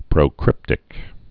(prō-krĭptĭk)